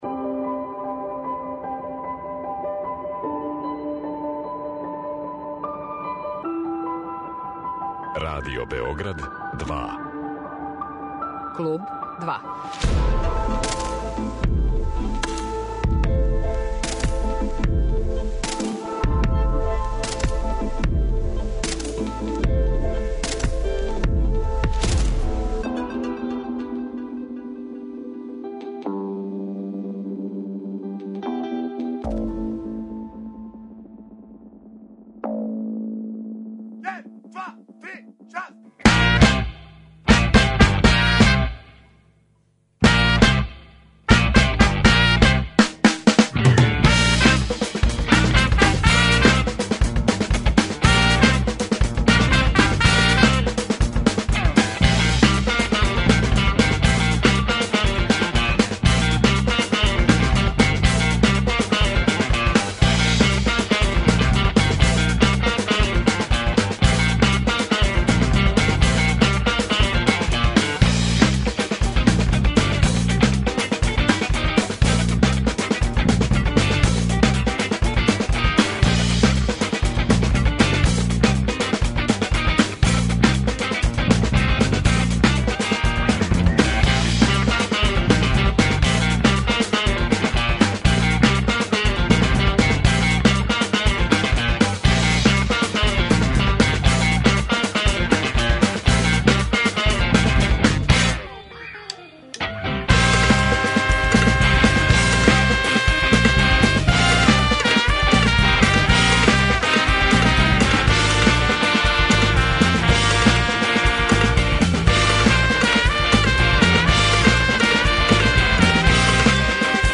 Гост Клуба 2